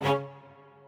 strings6_8.ogg